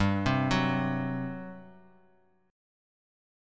GM7sus4 chord